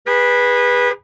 CosmicRageSounds / ogg / general / highway / oldcar / honk3.ogg
honk3.ogg